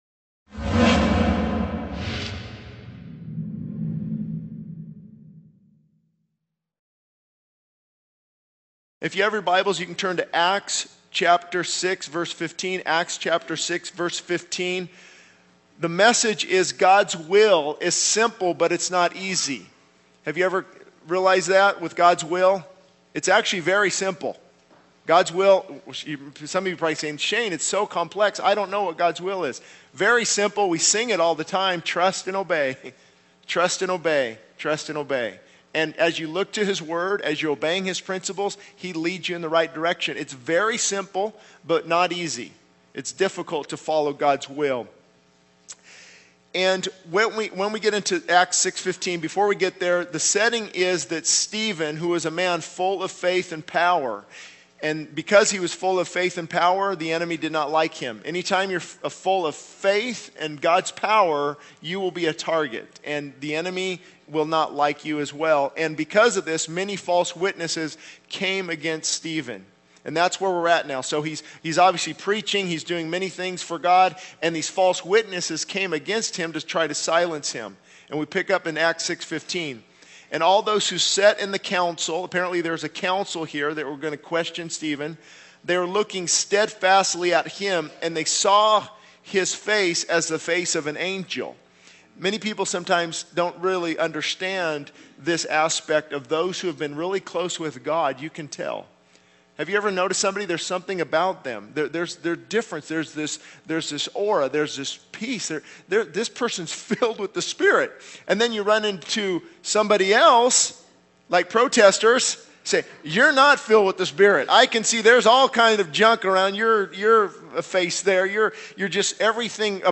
The sermon concludes with a call to trust in God's sovereignty and to be open to His leading, even when it is uncomfortable.